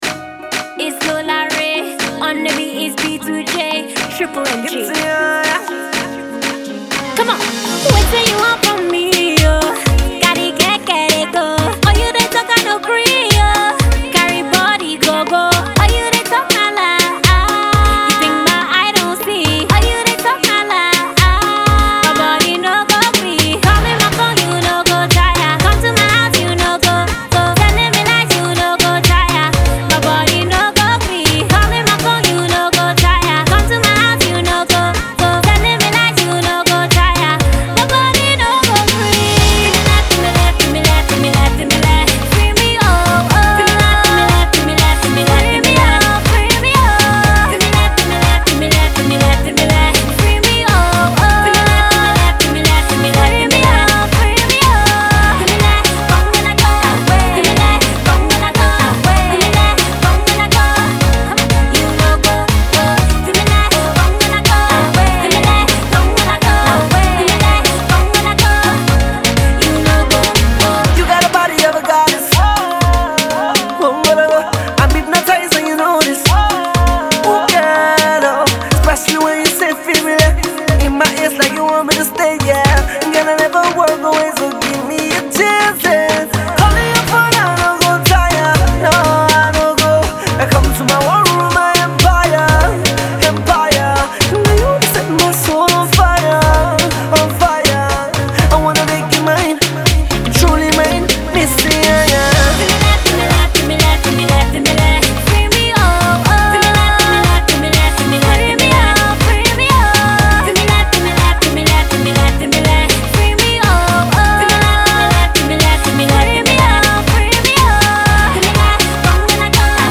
catchy new Afro Pop tune